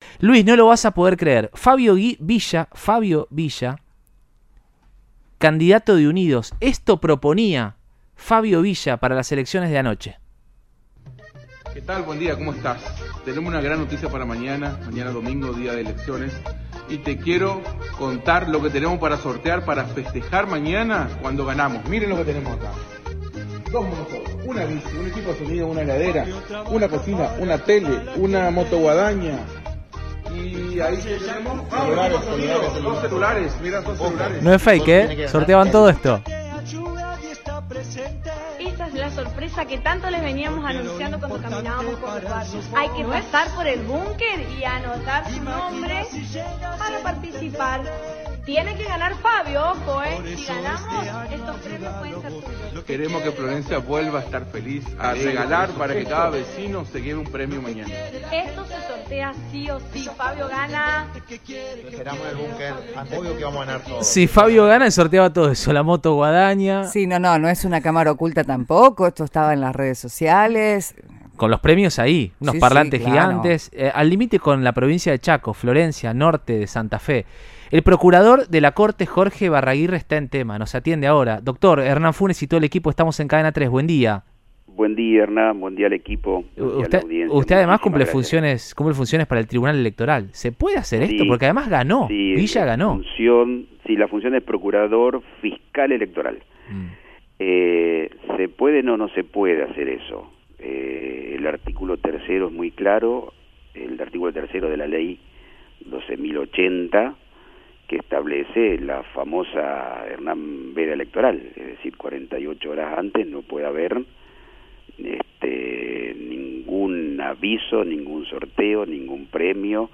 Fabio Villa, intendente electo de Florencia, Santa Fe, expresó su postura en Cadena 3 Rosario y dijo: La gente eligió el cambio y fue contundente. El Tribunal Electoral analiza una denuncia presentada por la oposición.